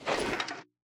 equip_iron3.ogg